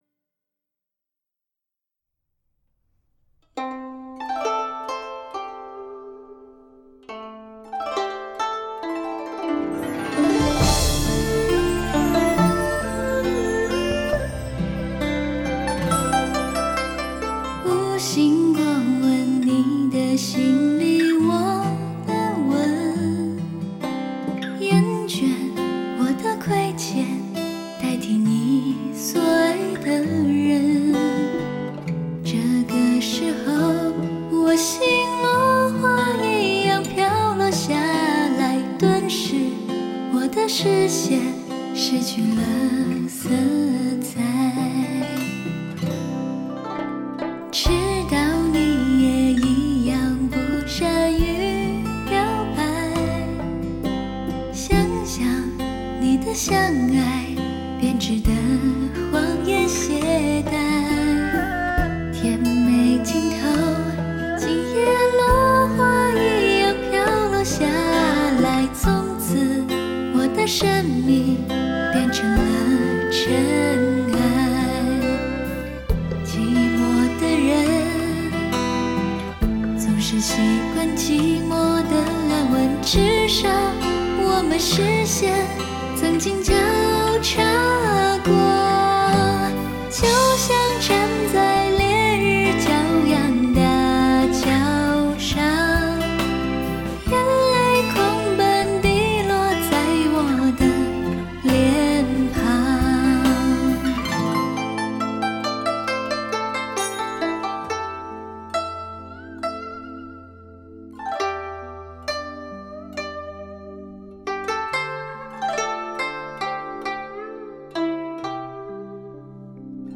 于躁动的城市间轻吟，于喧嚣的尘世间低唱，